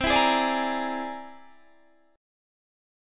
C+9 Chord
Listen to C+9 strummed